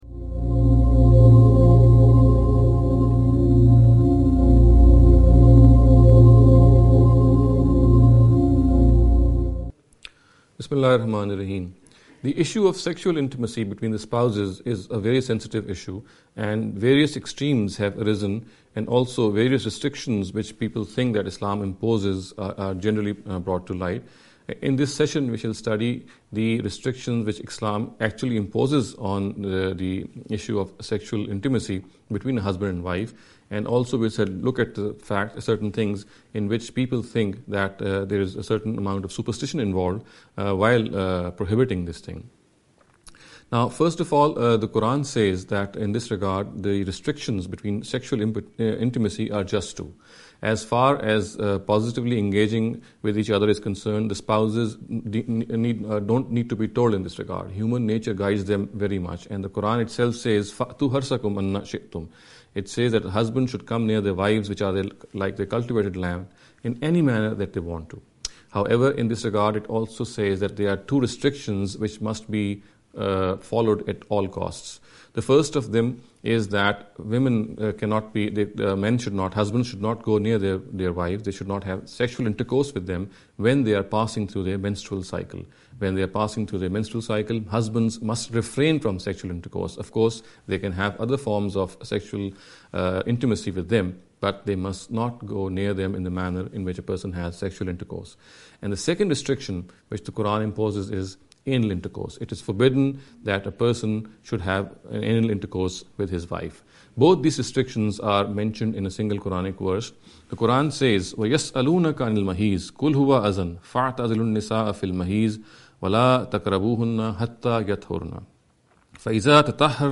This lecture series will deal with some misconception regarding the Islam & Women.